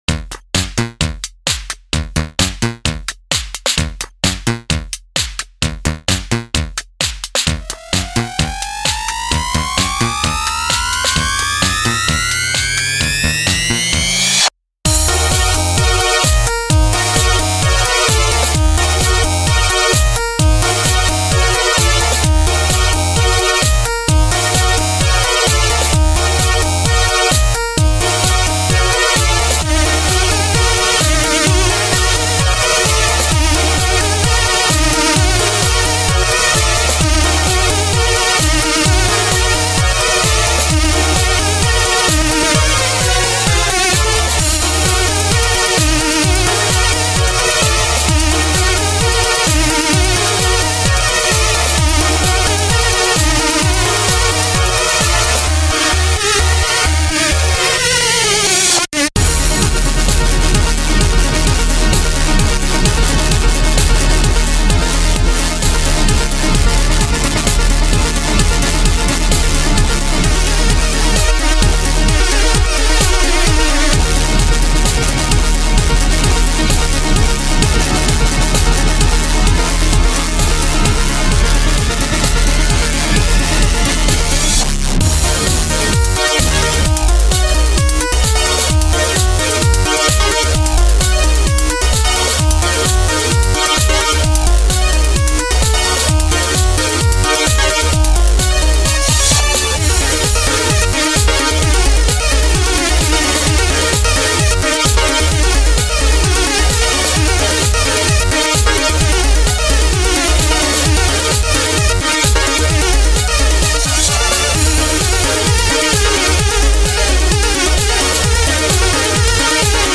So why are there no vocals?